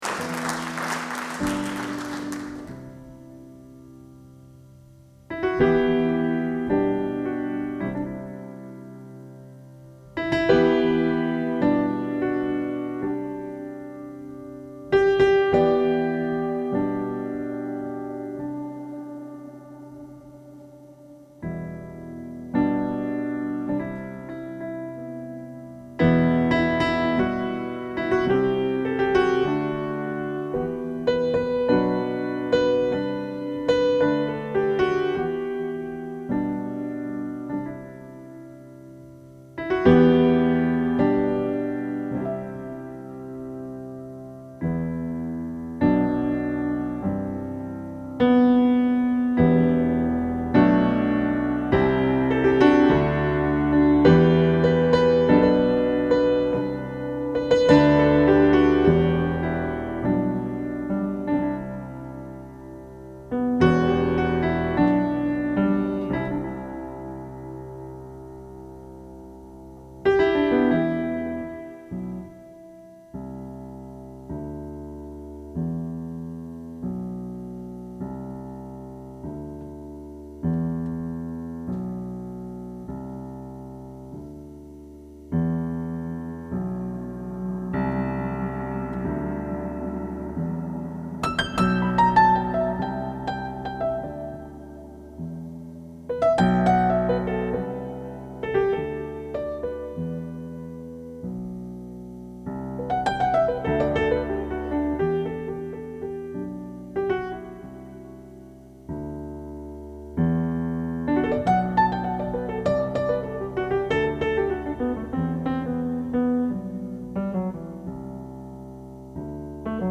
Klassik und Club, Ambient und Improvisation